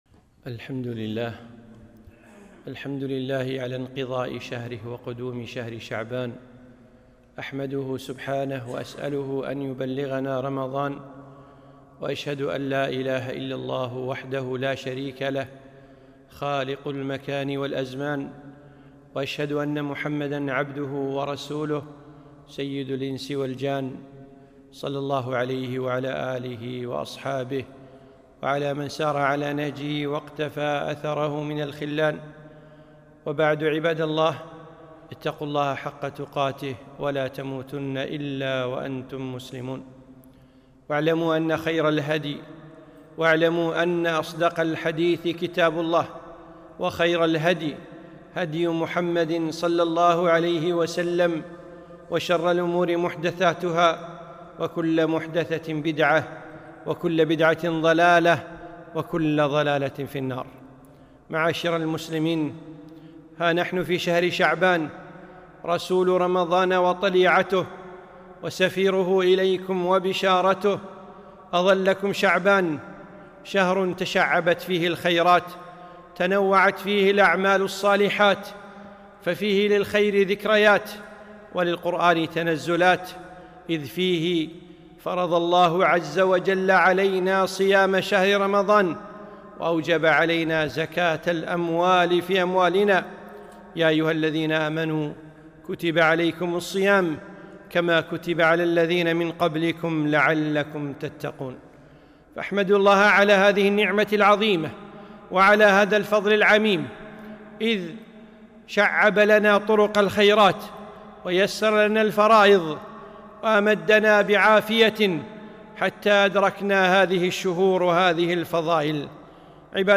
خطبة - شهر شعبان